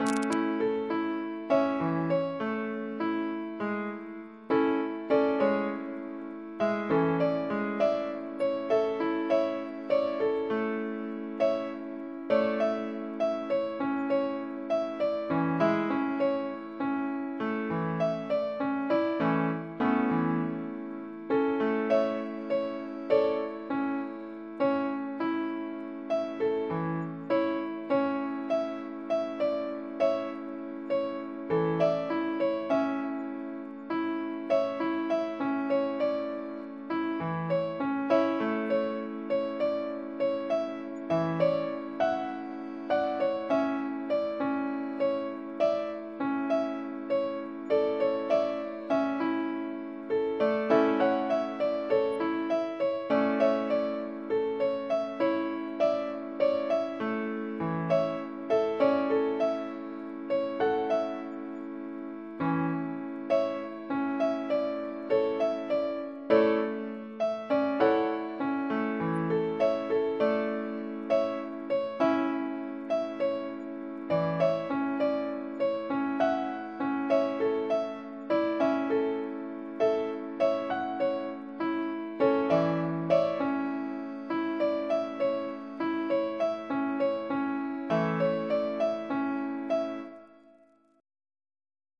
mp3) with the hpps tunes in the Notthingham database with the cross entropy error cost function (300 gradient updates and 50 example points leading the generation) is shown below: